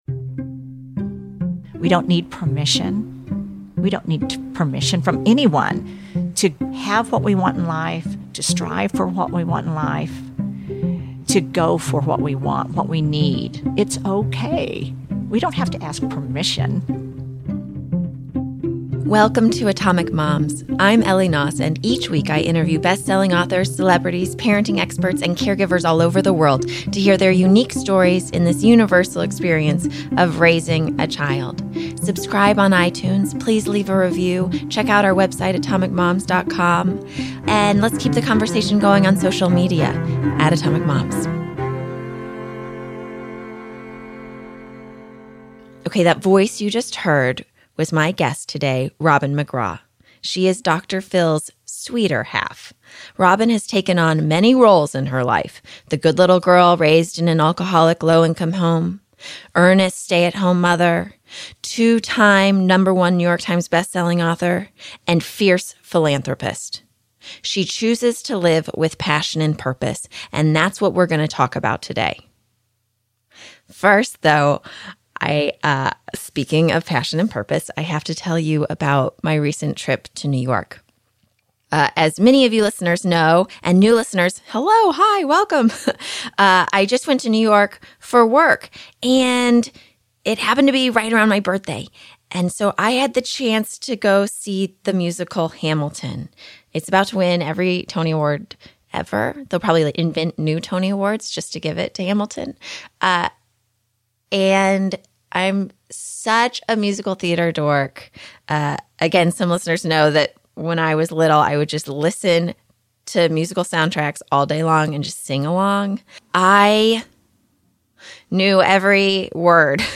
Dr. Phil's sweeter half ROBIN MCGRAW, a two-time #1 New York Times bestselling author, devoted mother and grandmother, and fierce philanthropist, sits down with Atomic Moms for this in-depth interview.